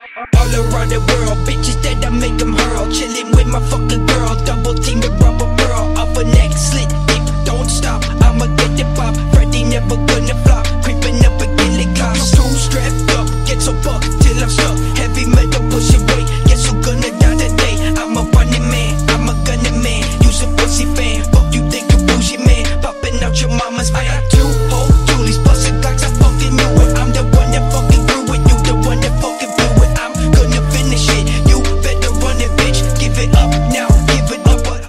• Качество: 192, Stereo
громкие
басы
качающие
Стиль: phonk, rap